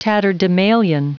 Prononciation du mot tatterdemalion en anglais (fichier audio)
Prononciation du mot : tatterdemalion